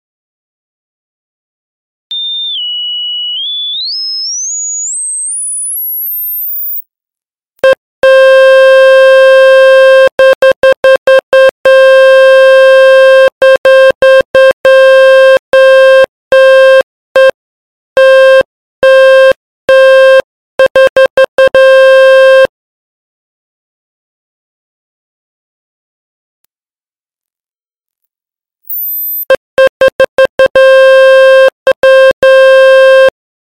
На этой странице собраны звуки, создающие ощущение абсолютной чистоты: от мягкого шума убирающегося помещения до переливов хрустальных нот.
Звук для очистки динамика от пыли и грязи